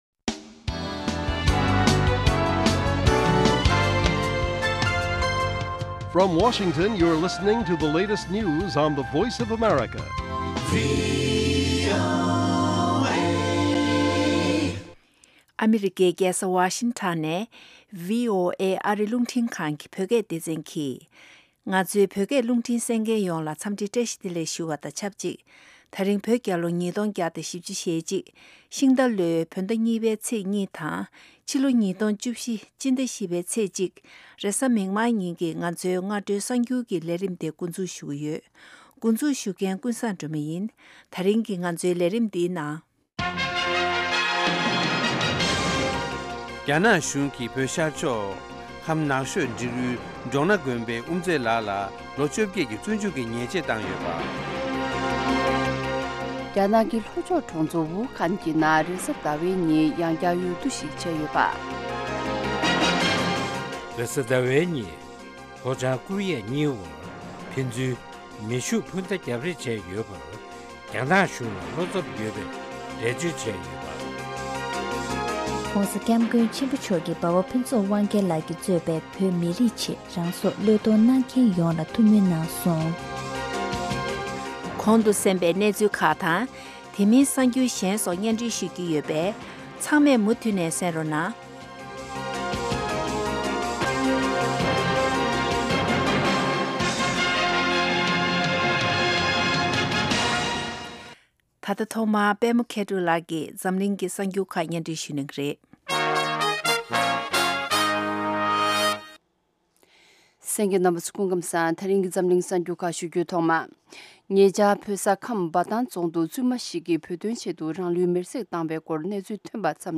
སྔ་དྲོའི་གསར་འགྱུར།